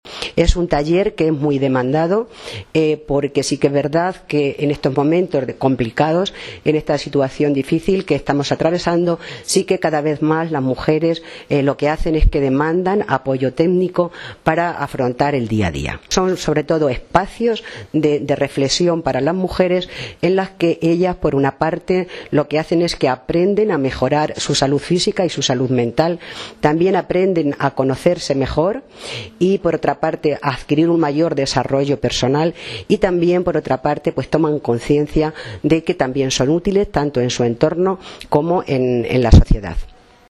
La responsable municipal del área de Servicios Sociales ha subrayado a los medios de comunicación que el objetivo que se plantea desde la concejalía es desarrollar las actividades “más demandas para que den respuesta a las necesidades de las mujeres”.